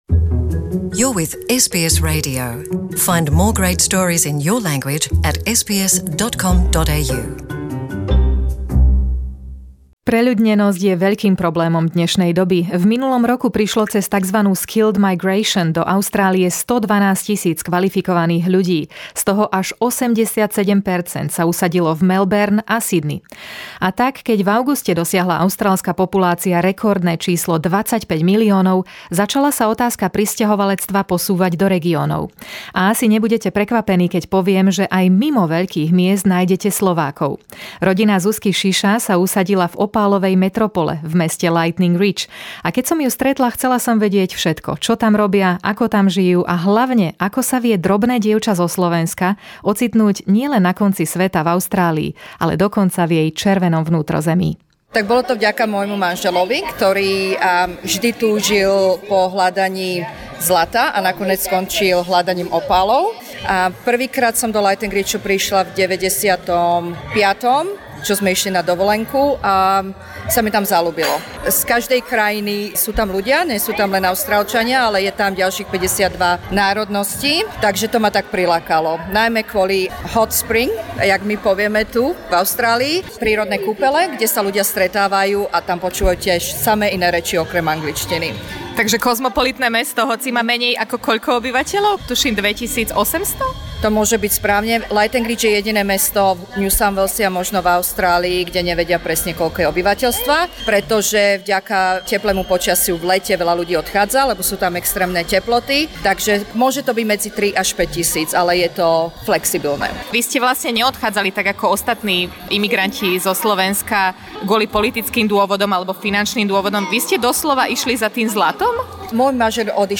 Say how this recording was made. visited Slovak community in Lightning Ridge as they reflect on the successes of their opal mining efforts. 70km from the nearest "big" town of Walgett, they learned to be self-sufficient and have the quality of life that big cities take for granted.